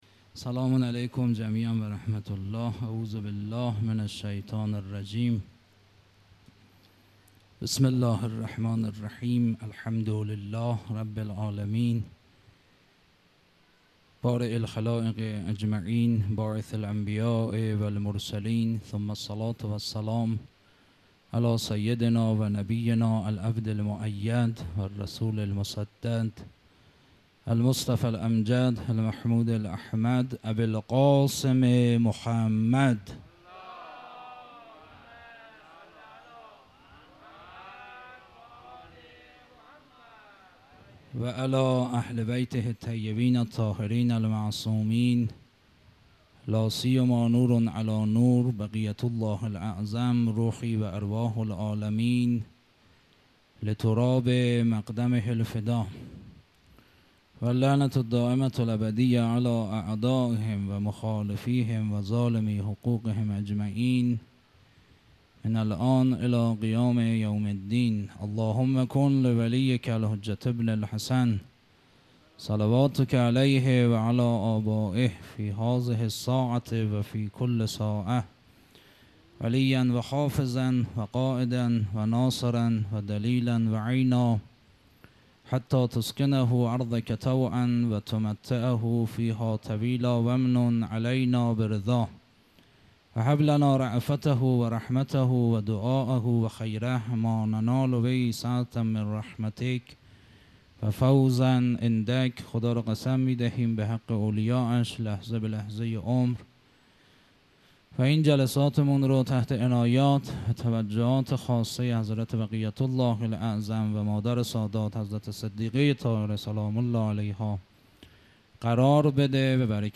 مراسم عزاداری محرم الحرام ۱۴۴۳_شب سوم